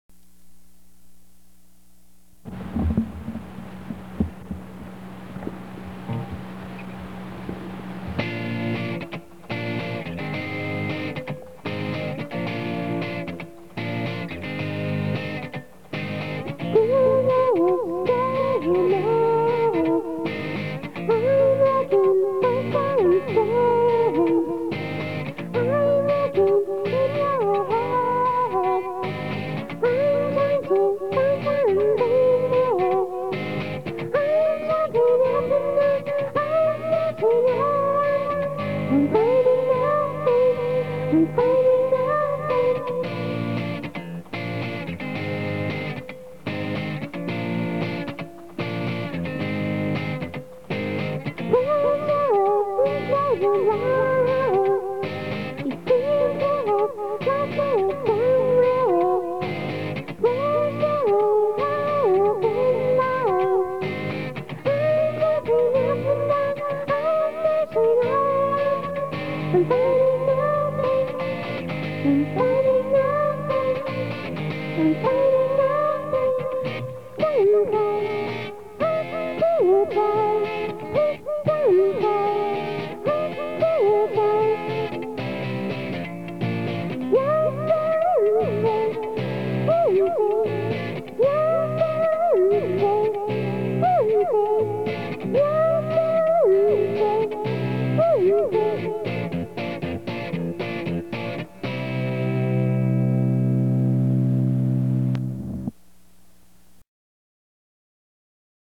This song is purely me. I recorded through a sound effects mixer onto a VERY old panasonic tape player (through the "input" channel!). this is also a very early recording, and was a total blast to do (i looove effects!). recorded in early 1998.